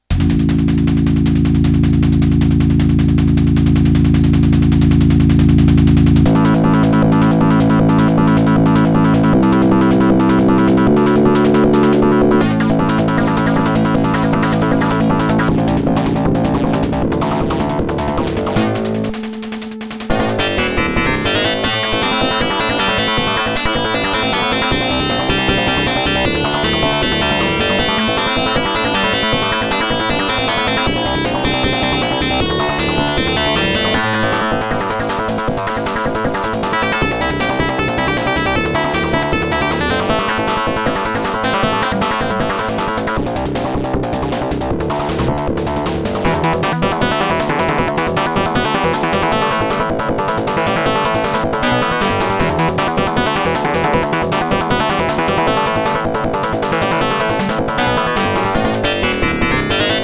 picoGUS (прошивка 1.0.2, режим AdLib) (